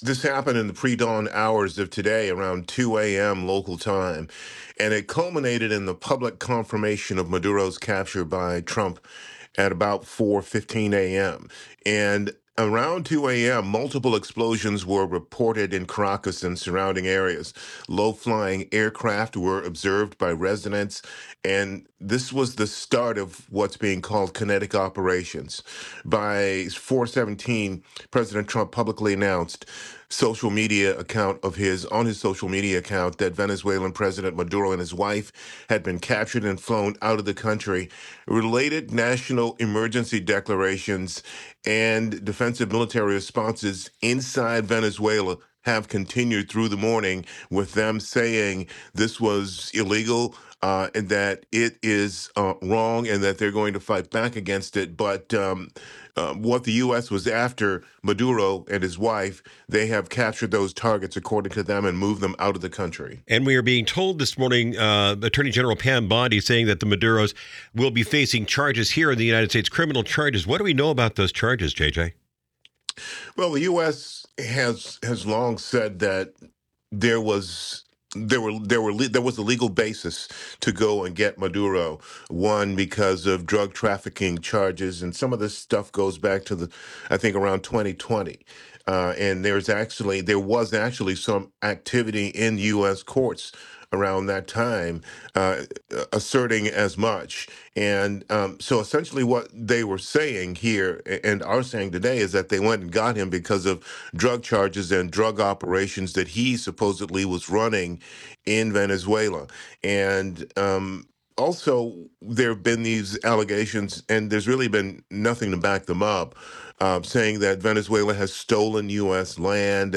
This interview has been slightly edited for clarity.